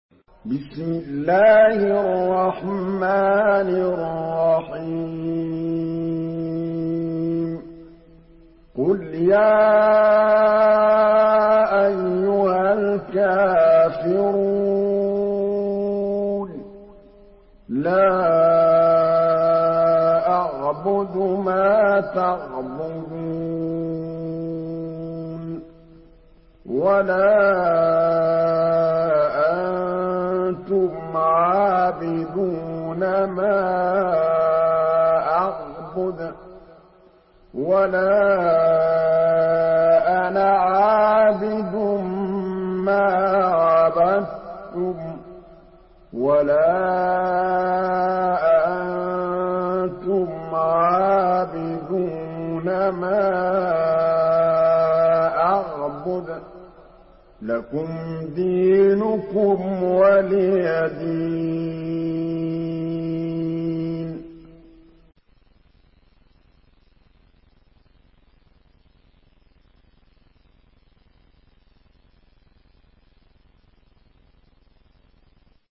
Surah Al-Kafirun MP3 in the Voice of Muhammad Mahmood Al Tablawi in Hafs Narration
Listen and download the full recitation in MP3 format via direct and fast links in multiple qualities to your mobile phone.
Murattal